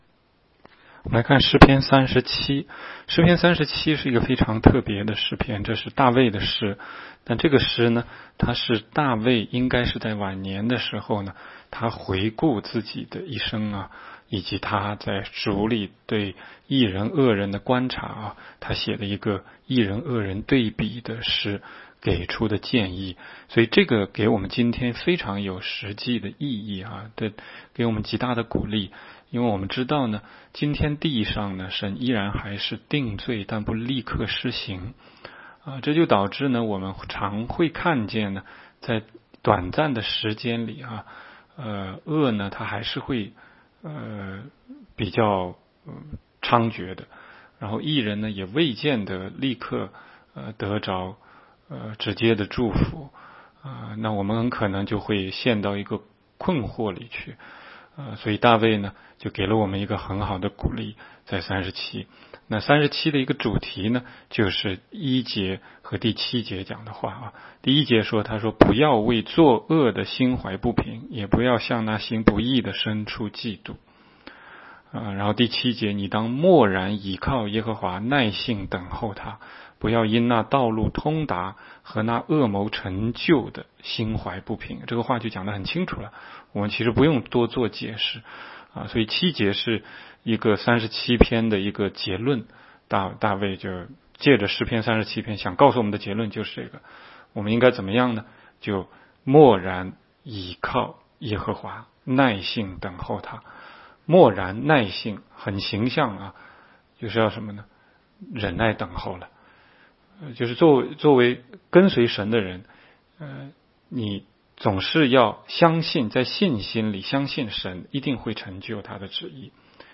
16街讲道录音 - 每日读经-《诗篇》37章